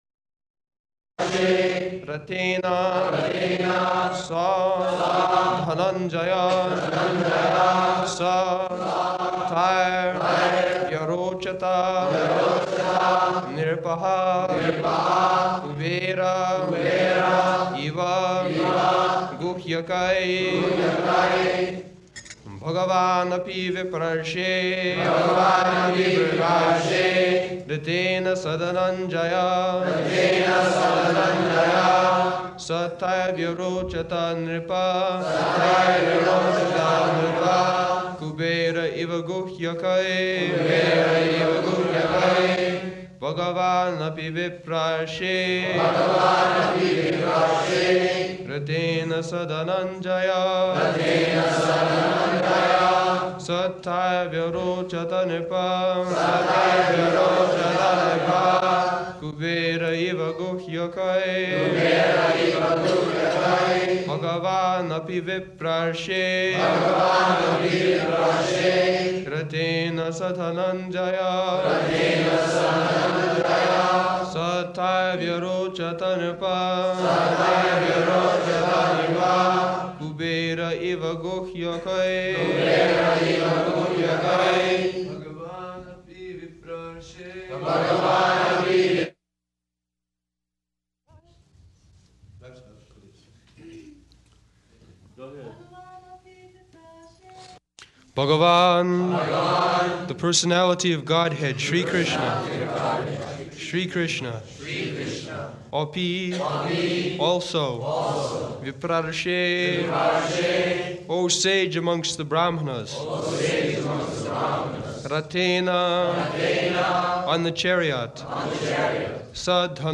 Location: Los Angeles
[Prabhupāda and devotees repeat]